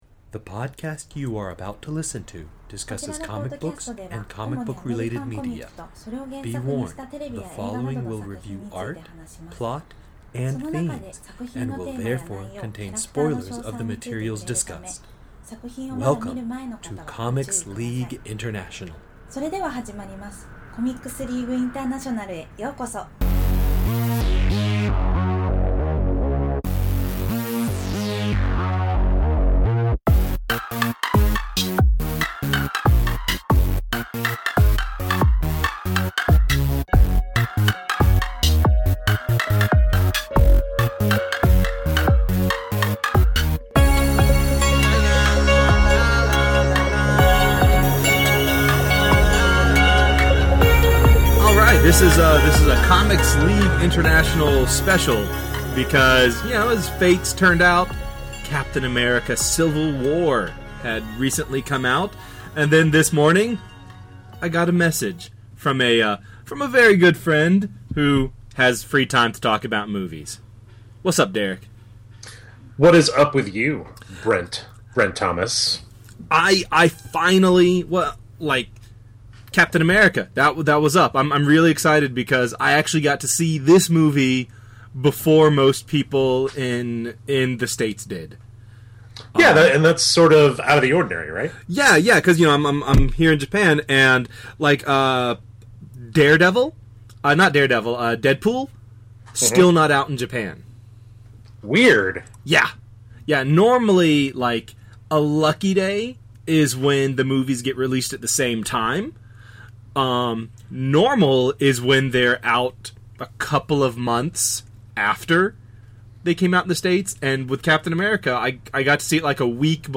CLI’s intro and outro music